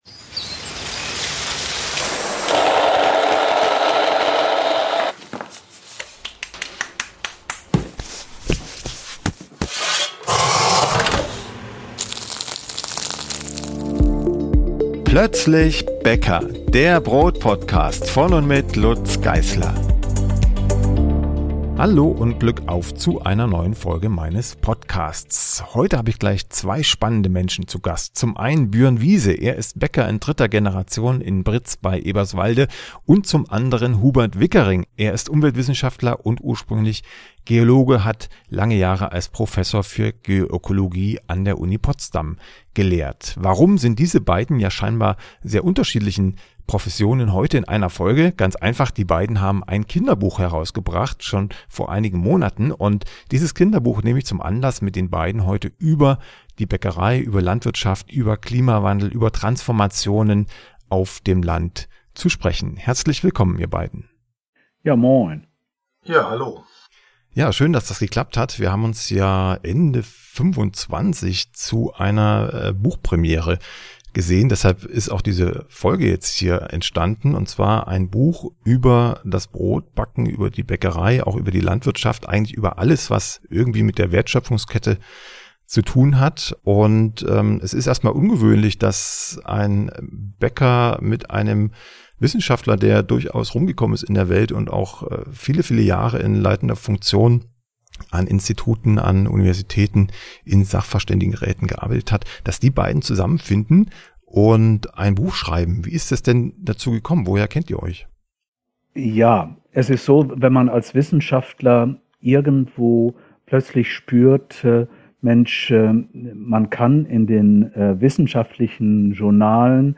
Im Gespräch gehen wir u.a. der Frage nach, wie der Klimawandel und Wetterextreme die künftige Landwirtschaft und die Bäckerei verändern werden, welche Transformation in der Vergangenheit geglückt oder in die falsche Richtung gelaufen ist oder wie komplexe Themen für Erwachsene wie Kinder in spannende Geschichten heruntergebrochen werden können.